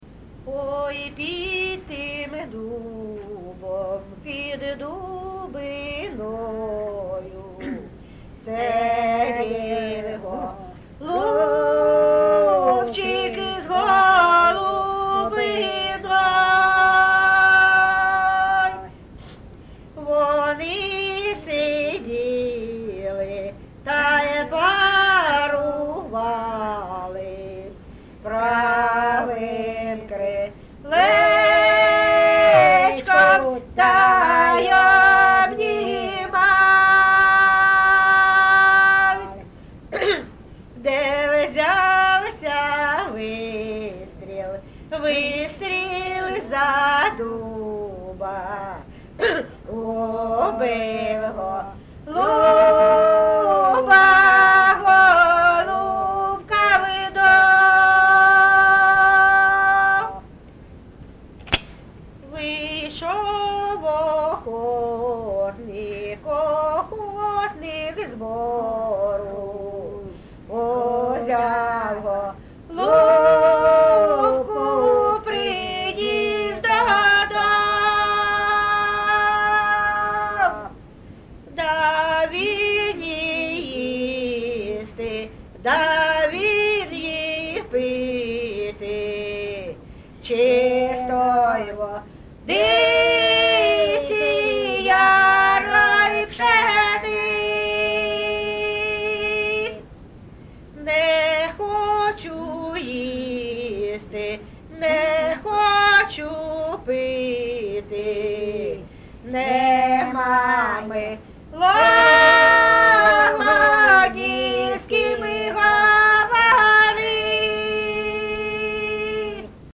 ЖанрПісні з особистого та родинного життя, Балади
Місце записус. Закітне, Краснолиманський (Краматорський) район, Донецька обл., Україна, Слобожанщина